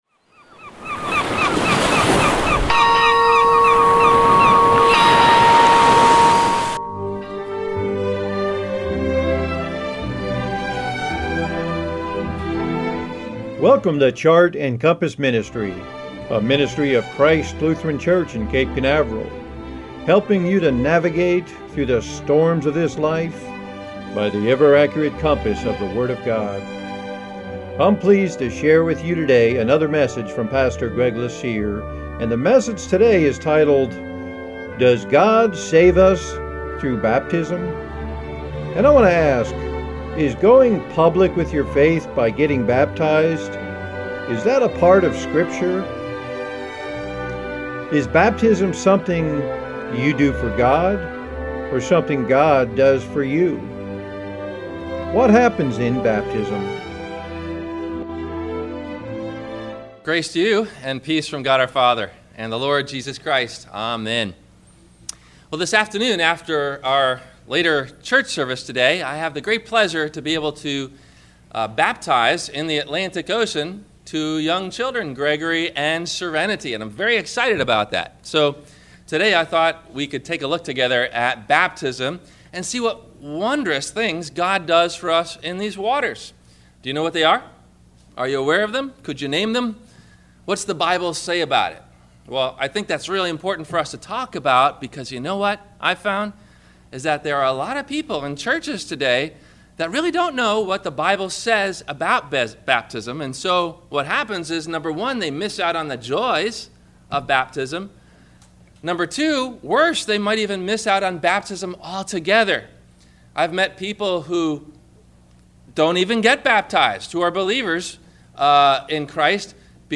Does God Save Us Through Baptism? – WMIE Radio Sermon – February 01 2016